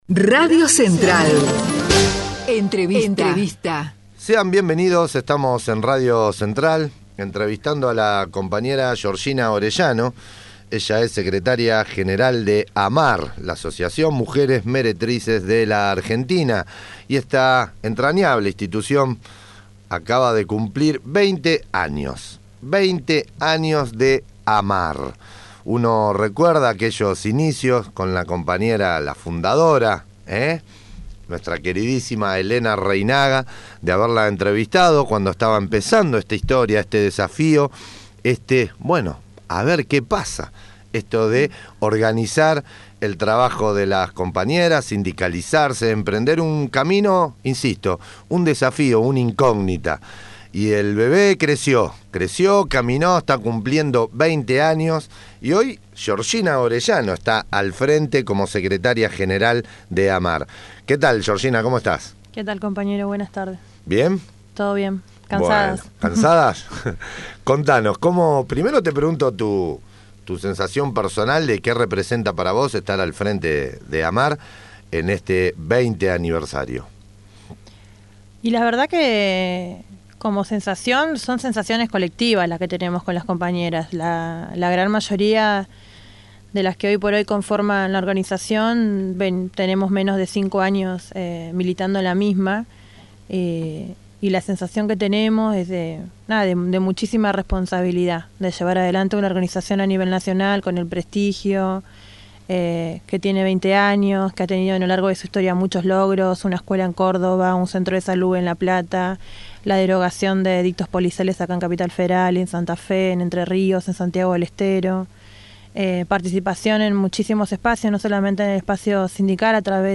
AMMAR, 20 AÑOS // entrevista